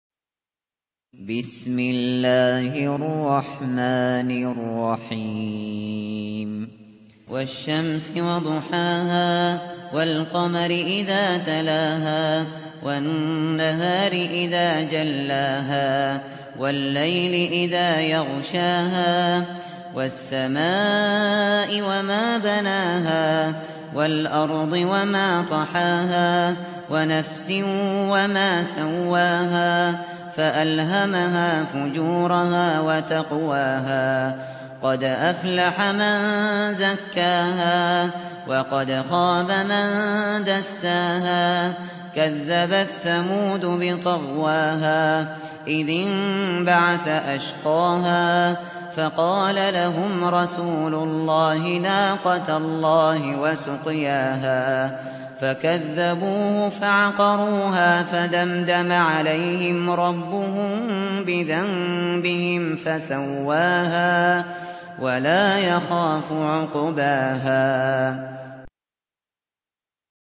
Récitation par Abu Bakr Al Shatri